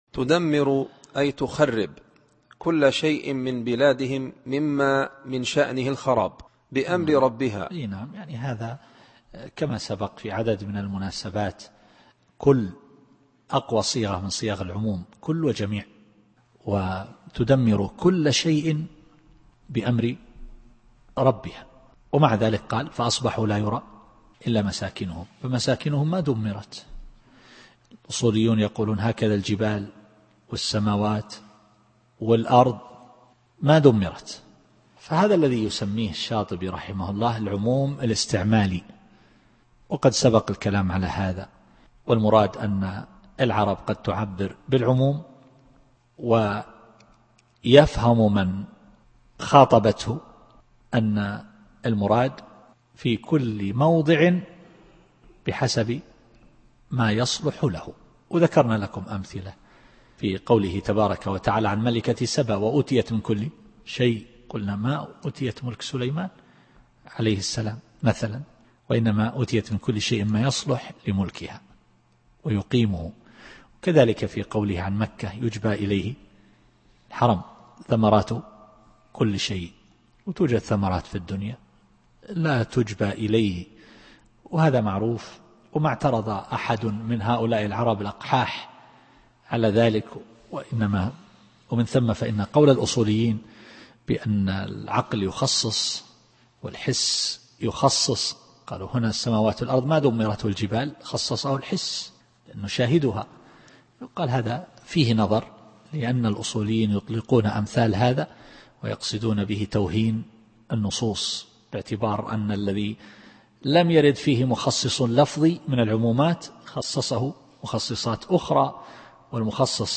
التفسير الصوتي [الأحقاف / 25]